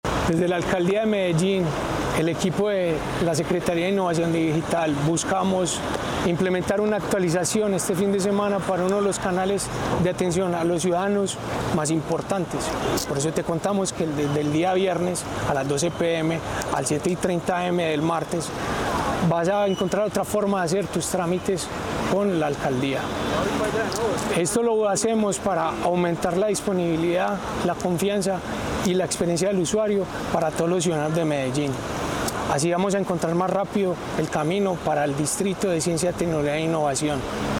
Palabras de Jaime Orozco, subsecretario de Tecnologías de Información